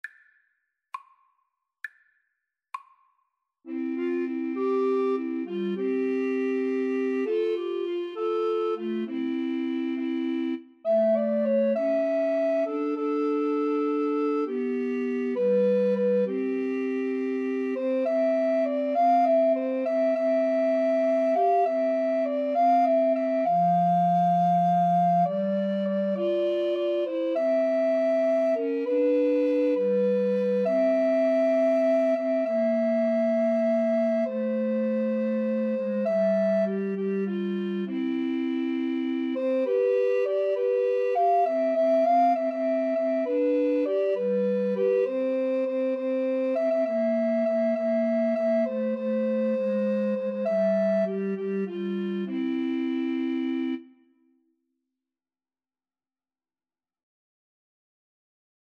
Alto RecorderTenor RecorderBass Recorder
C major (Sounding Pitch) (View more C major Music for Recorder Trio )
6/8 (View more 6/8 Music)